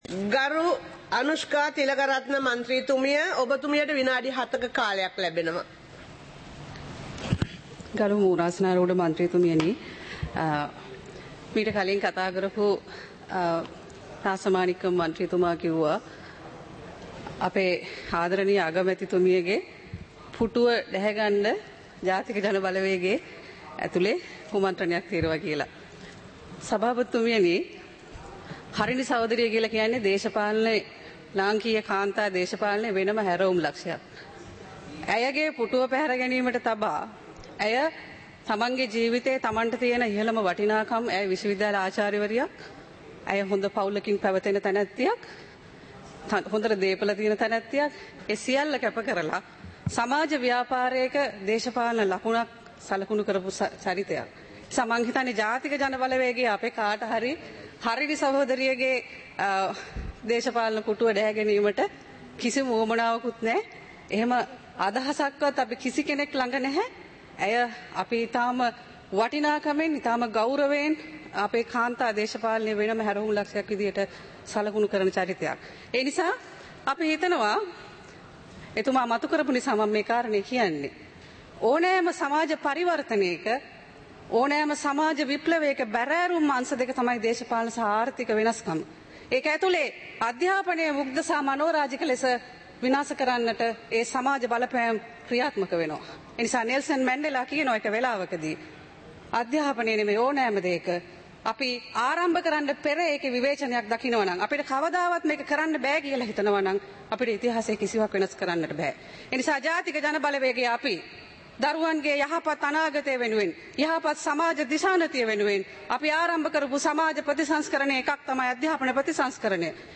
நேரலை - பதிவுருத்தப்பட்ட